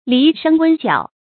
犁生骍角 注音： ㄌㄧˊ ㄕㄥ ㄒㄧㄥ ㄐㄧㄠˇ 讀音讀法： 意思解釋： 謂雜色牛生純赤色、角周正的小牛。